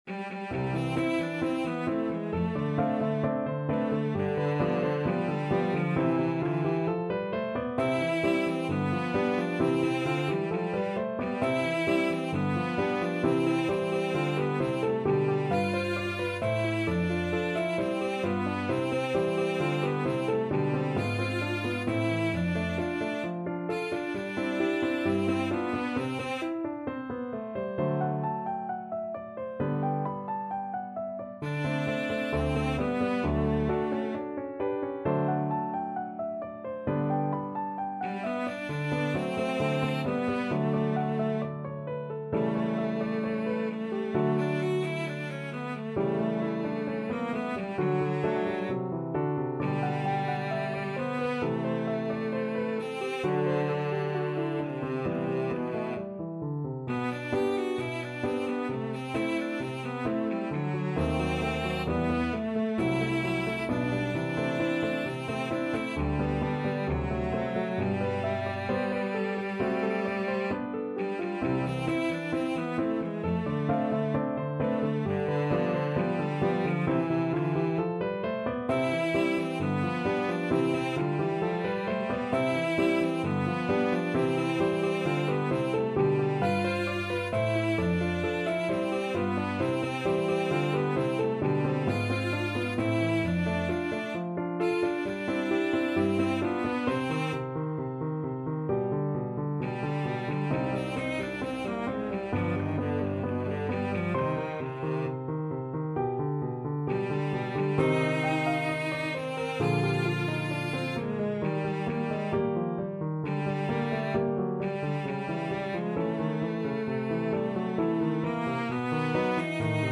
Cello
C major (Sounding Pitch) (View more C major Music for Cello )
2/2 (View more 2/2 Music)
=132 Allegro assai (View more music marked Allegro)
Classical (View more Classical Cello Music)